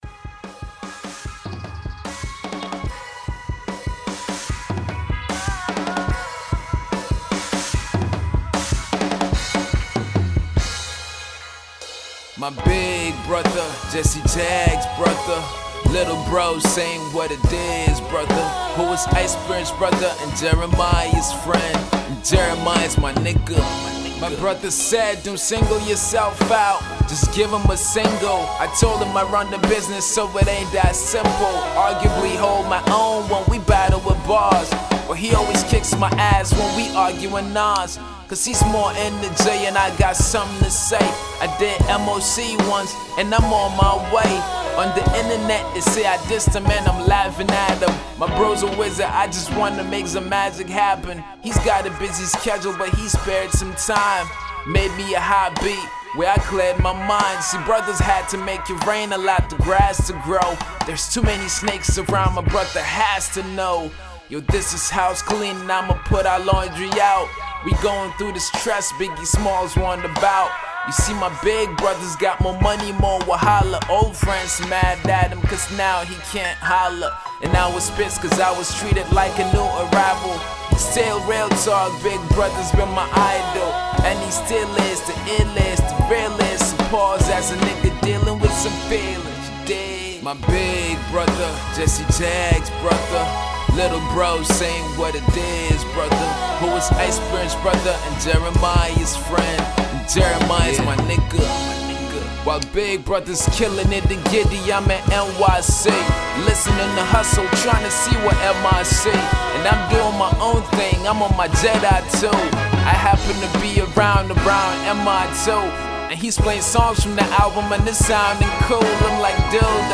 all the while staying true to its core hip-hop roots.